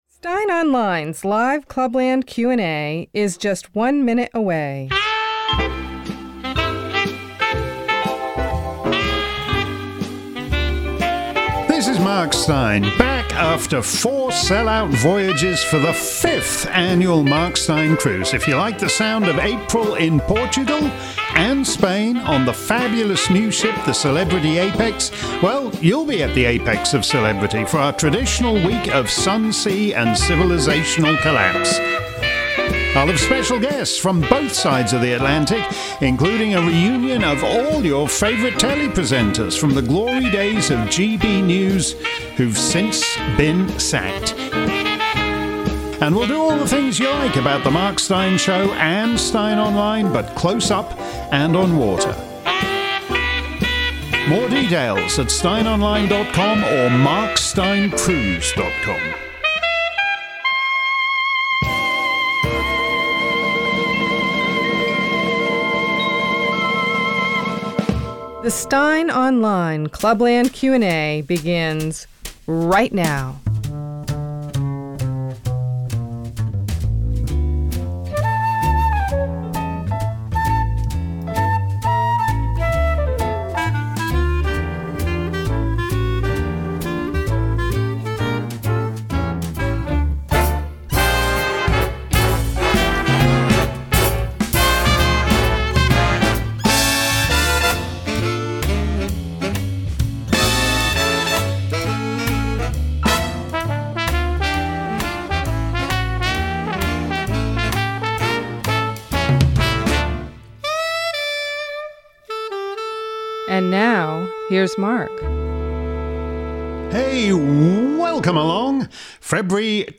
If you missed today's edition of Steyn's Clubland Q&A live around the planet, here's the action replay. This week's show focused mainly on the judicial coup presently underway in the United States, with Mark expanding on his 2020 observation to Tucker